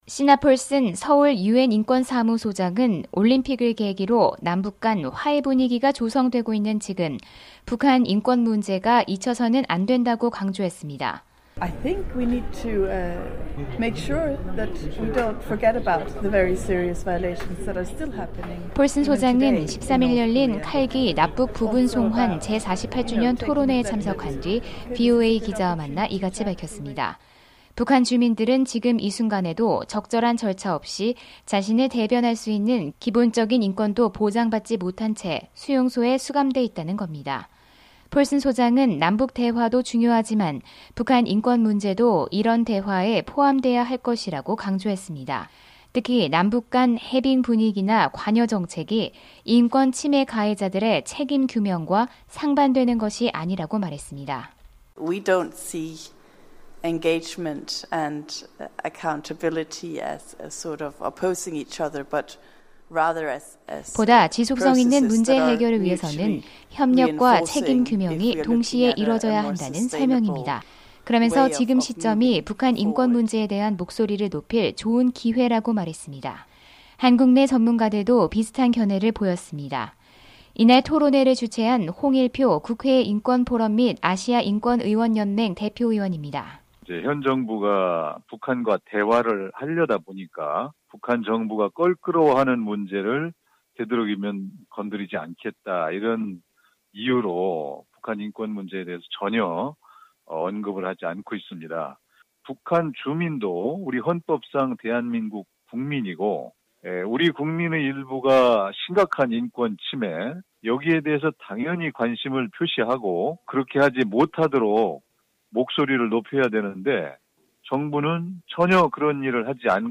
특파원 리포트